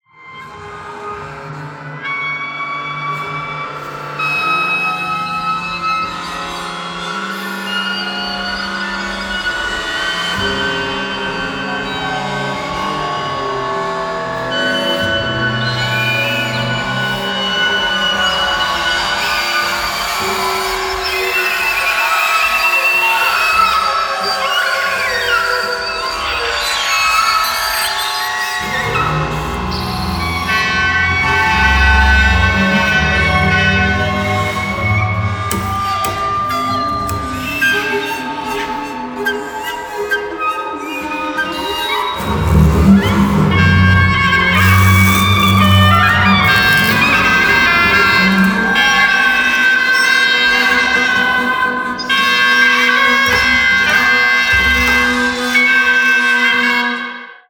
Photo of the Klangwerkstatt Festival, me on the left with the Hydra & two snippets:
my Hydra together with an ensemble of instruments such as Recorders, Double Bass and Oboe*